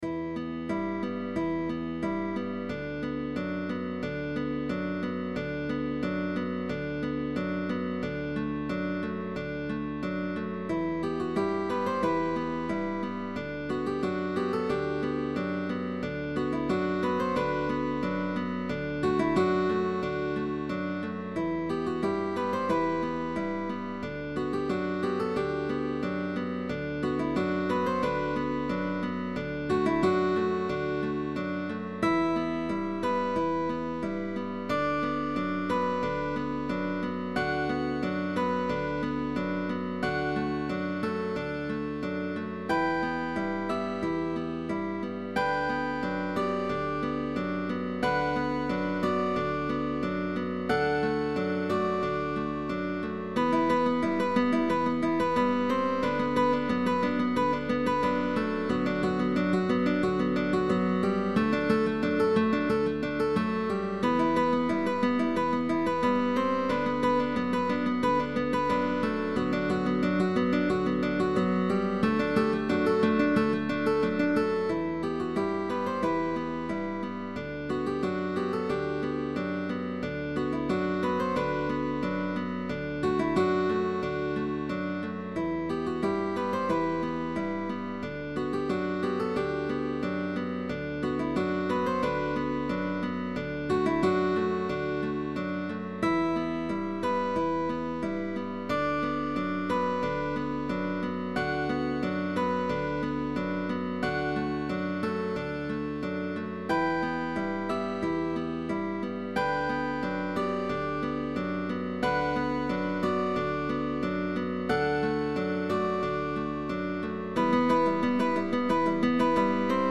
GUITAR QUARTET
Natural harmonics. Slurs.
Bass Guitar optional.